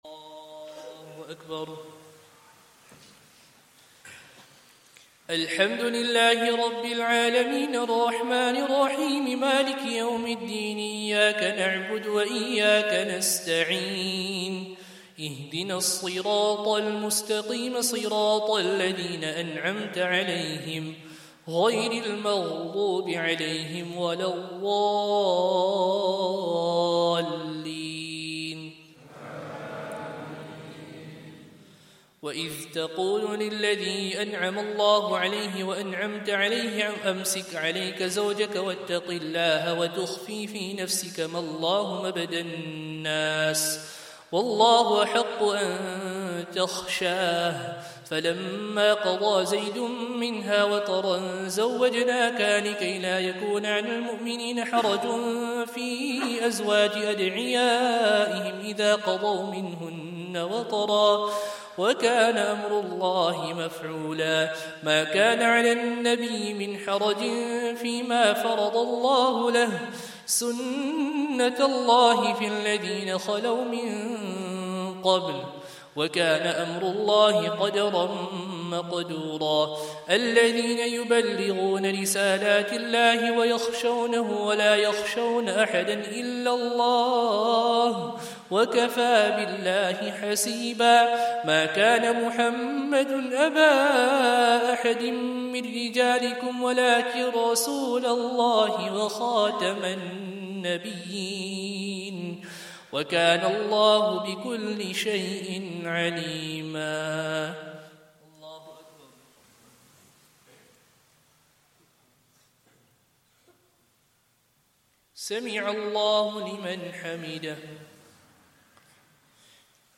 Day 18 - Taraweeh Recital - 1445